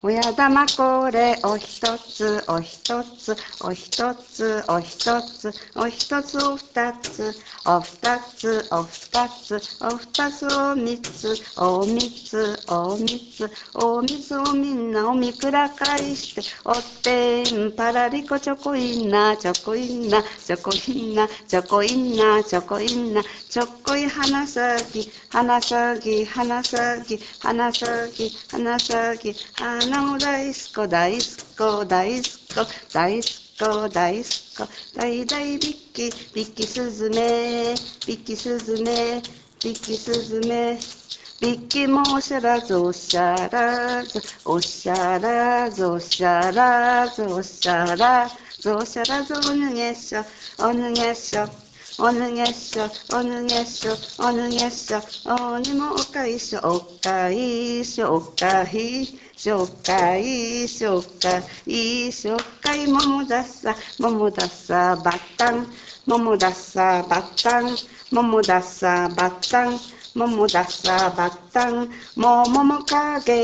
手球歌（杰克插孔）
听歌（杰克插孔）